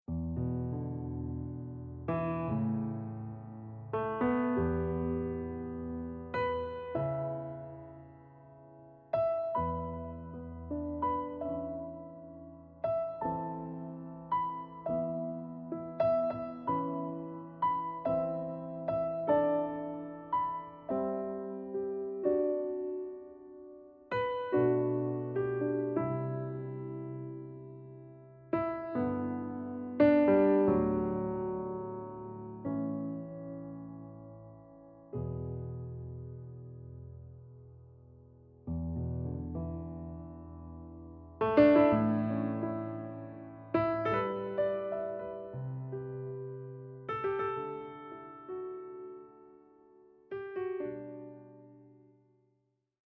Relaxed Electronic and Acoustic Music